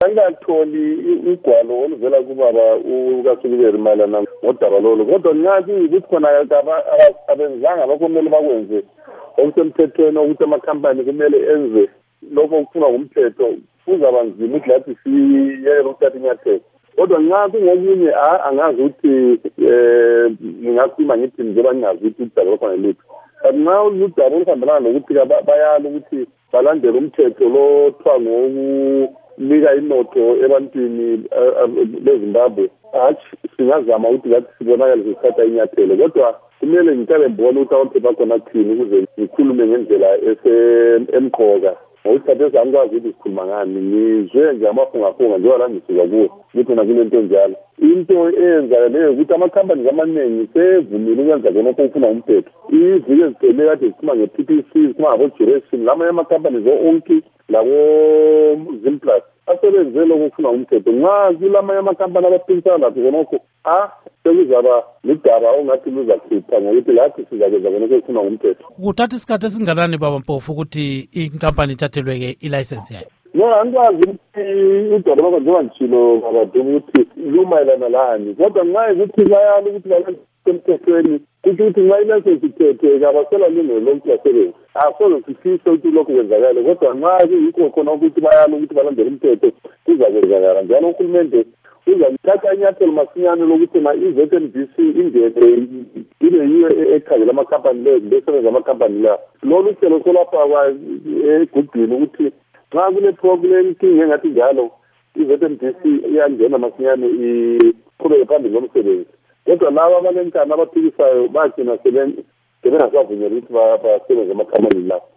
Ingxoxo Esiyenze LoMnu. Obert Mpofu